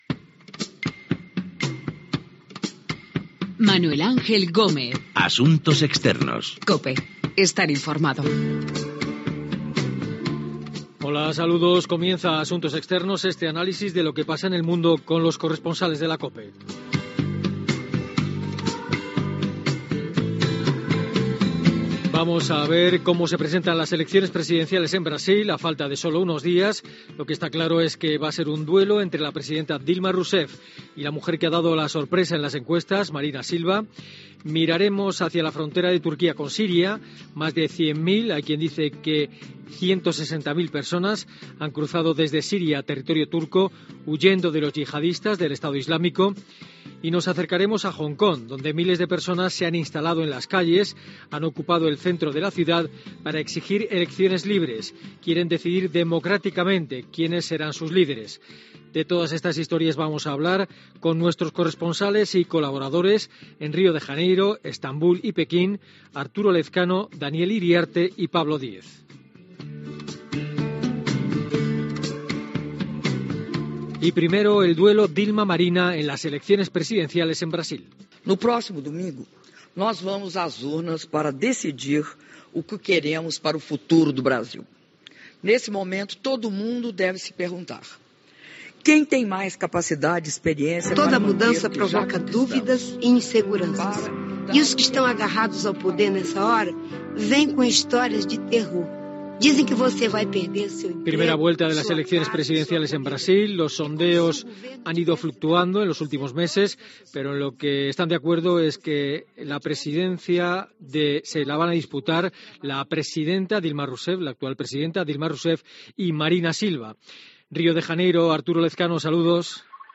Careta del programa, sumari de continguts, les polítiques Dilma Rousseff i Marina Silva es disputen la presidència de Brasil a les eleccions del 5 d'octubre
Informatiu